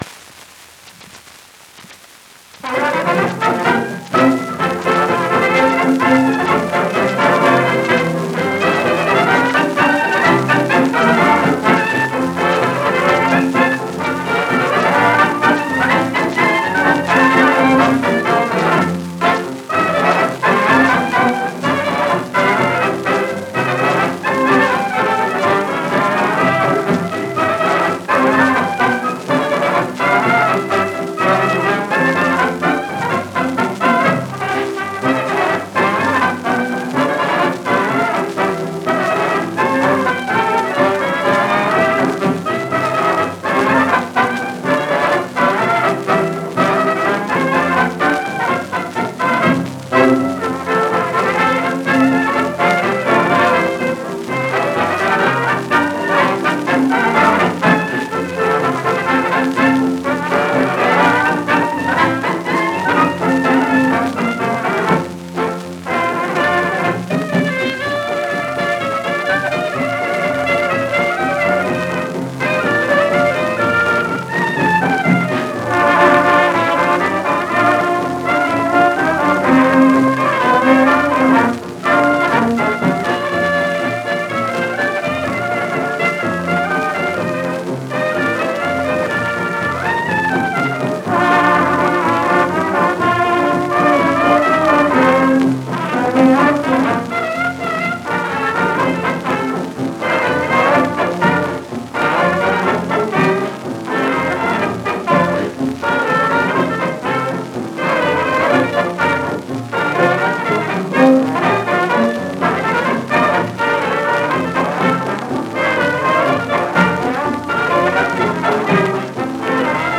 A little more upbeat than the former
instrumental polka